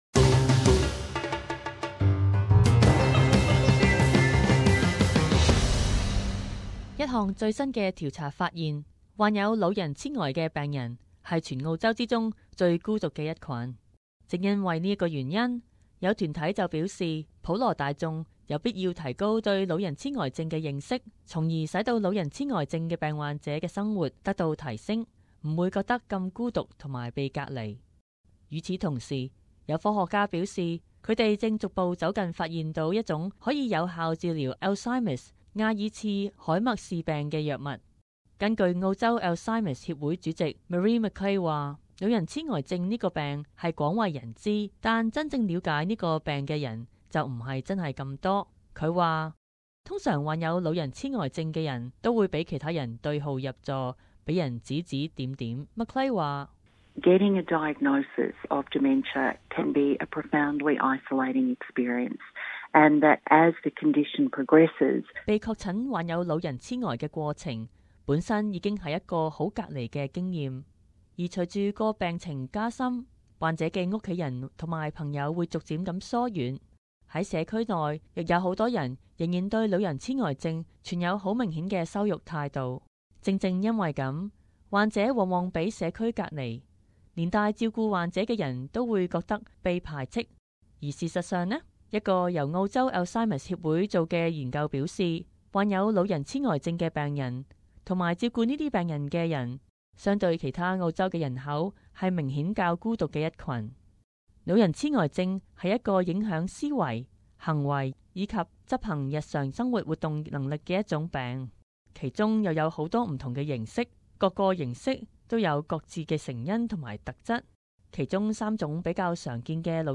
【時事報導】老人癡呆你知幾多？